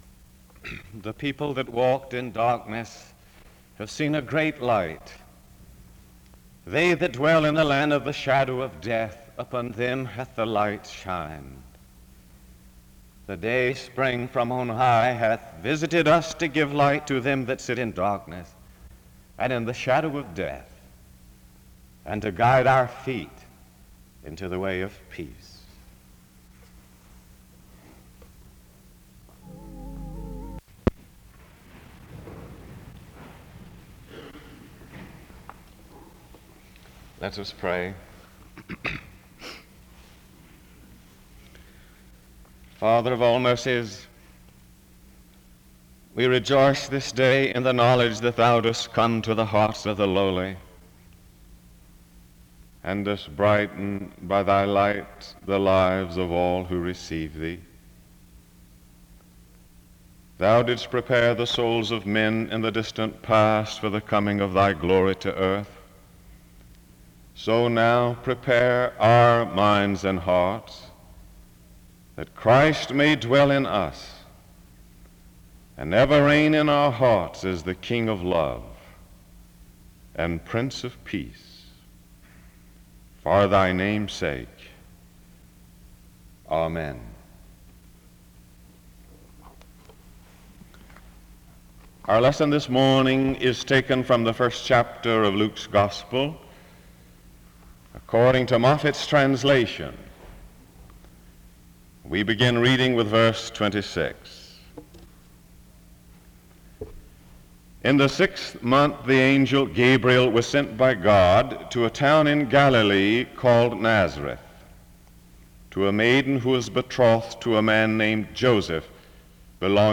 The service starts with a scripture reading and prayer from 0:00-1:27.
A closing prayer is offered from 14:57-15:48.